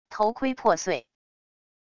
头盔破碎wav音频